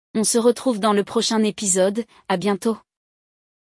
No episódio de hoje, acompanhamos a conversa entre duas amigas sobre um apartamento novo.
Além disso, praticará a pronúncia ao repetir as palavras e expressões junto com nossa professora.